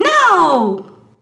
Mona shouting "No!" in WarioWare, Inc.: Mega Party Game$!